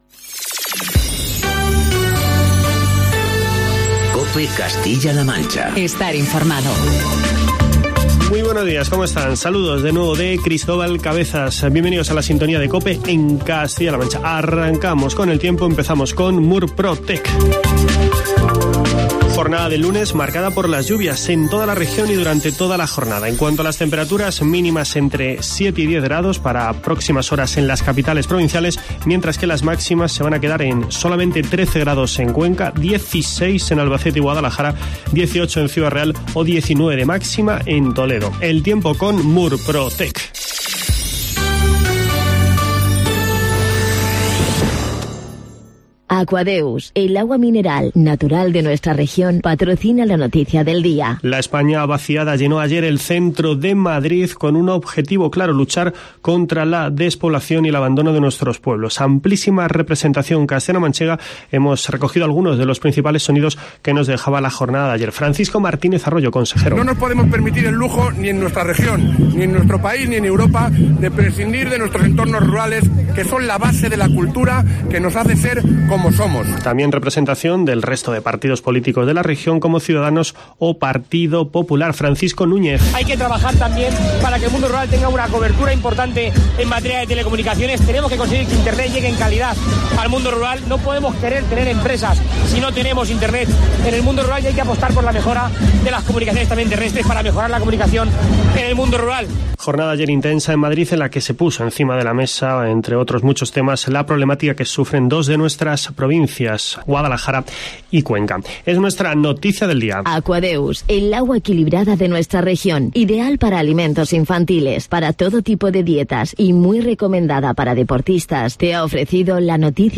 Informativo matinal de COPE Castilla-La Mancha y COPE Toledo.